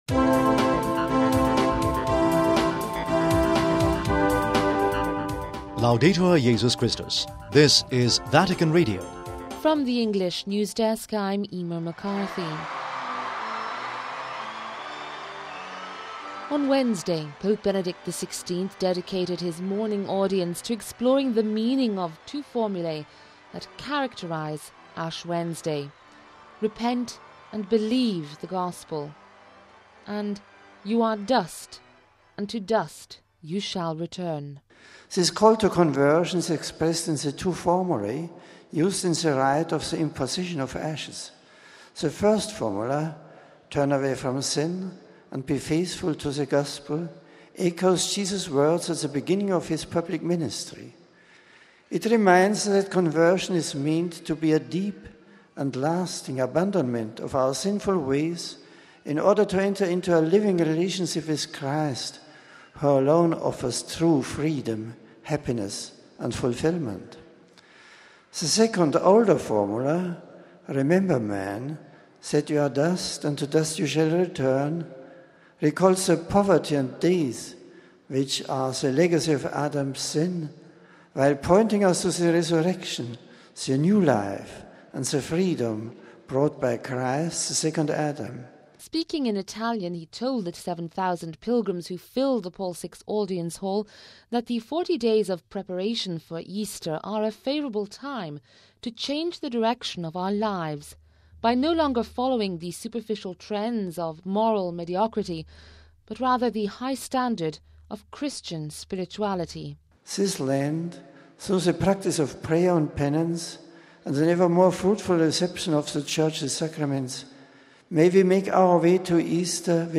Speaking in Italian he told the 7,000 pilgrims who filled the Paul VI Audience Hall that the 40 days of preparation for Easter are a favourable time to change the direction of our lives, by no longer following "superficial trends" or “moral mediocrity", but the "high standard "of Christian spirituality".